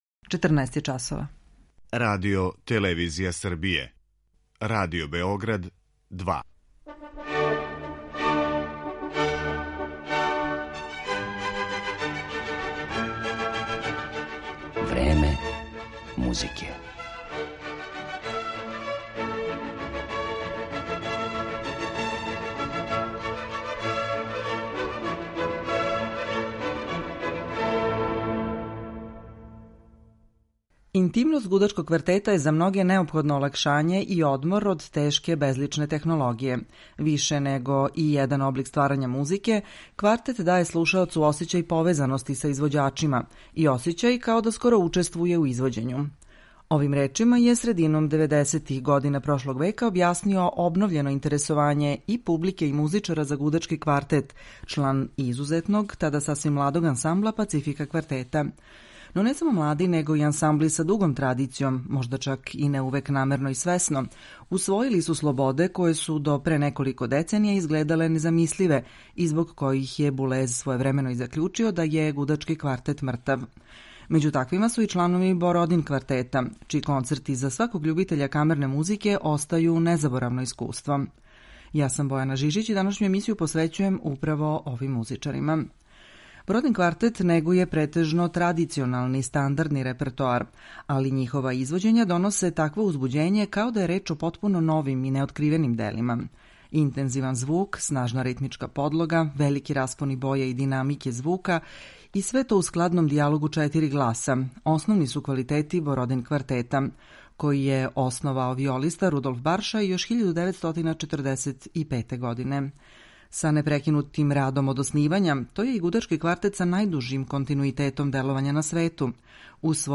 Ове врхунске руске музичаре слушаћете како изводе композиције аутора своје земље, али и Лудвига ван Бетовена и Антоњина Дворжака.